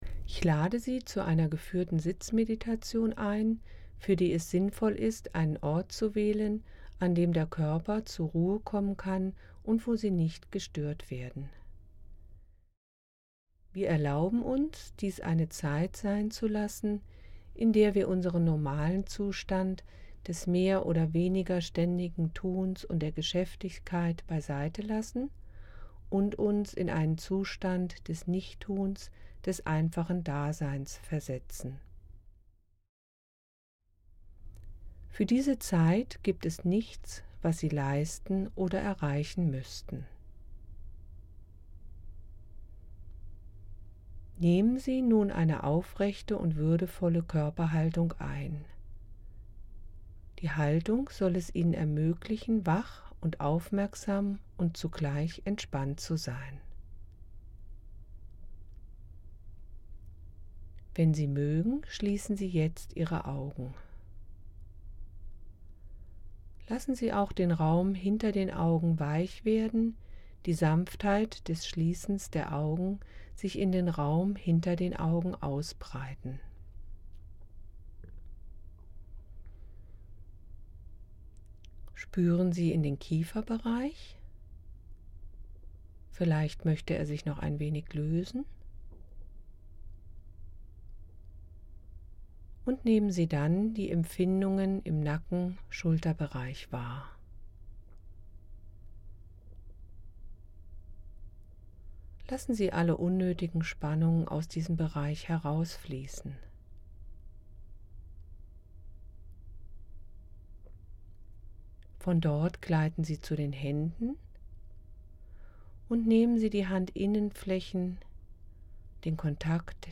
3_Sitzmeditation.mp3